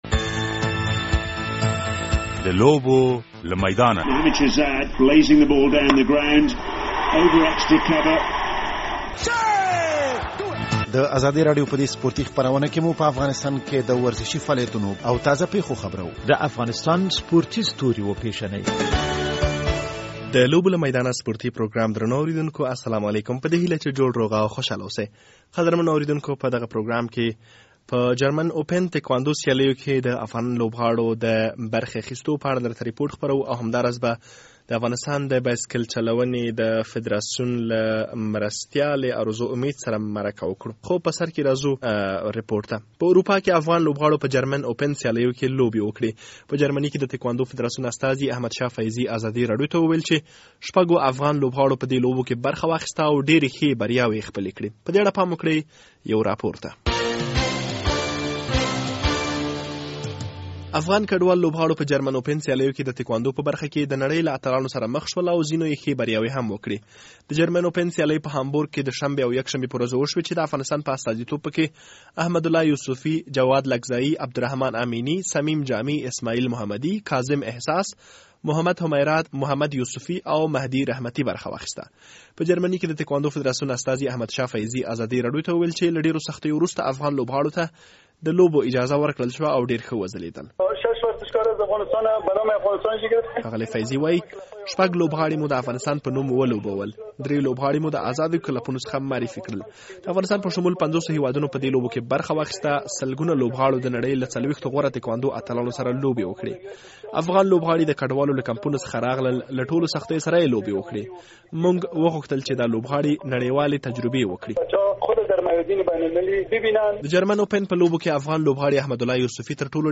په دغه پروګرام کې د افغانستان د ټکواندو لوبغاړو د لوبو په اړه رپوټ اورئ او هم به د بایسکل چلونې د ملي ټيم لوبغاړو په اړه مرکه واورئ چې تمه ده په "تور دو فرانس" لوبو کې برخه واخلي.